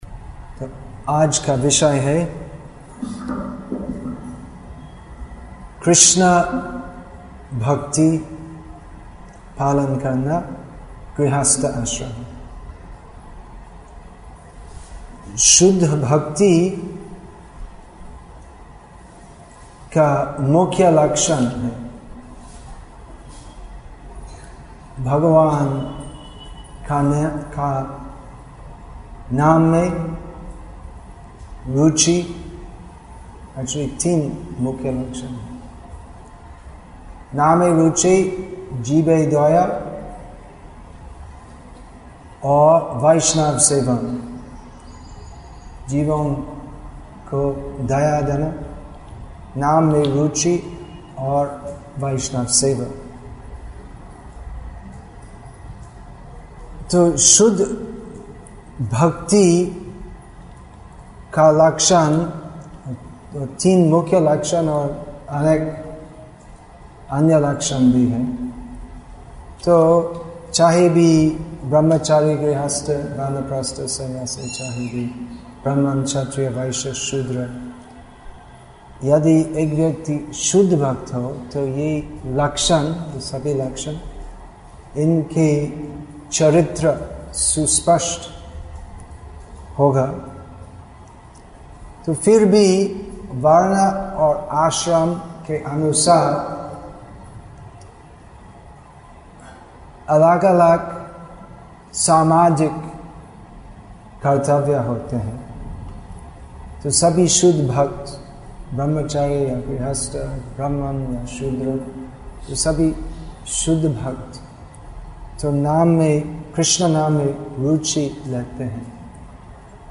Assorted Lectures
Social Issues हिन्दी Baroda/Vadodara, Gujarat , India Play Download Add To Playlist